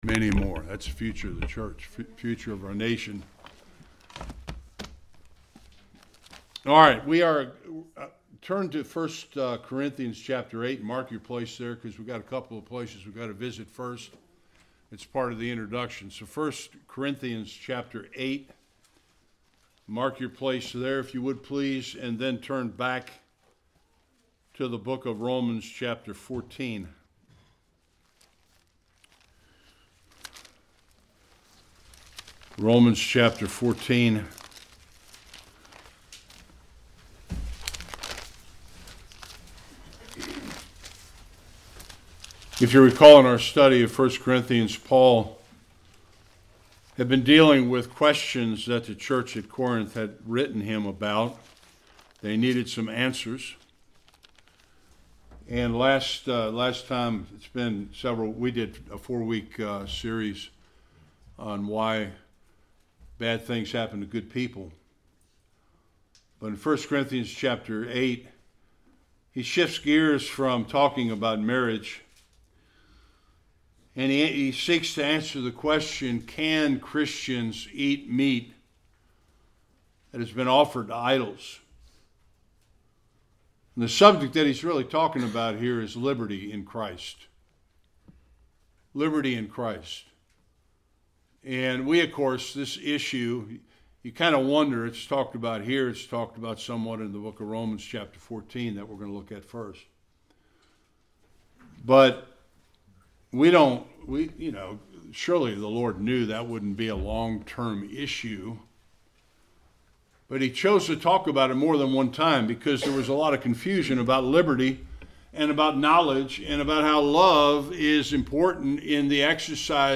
1-13 Service Type: Sunday Worship How do we handle issues that are not specifically dealt with in Scripture?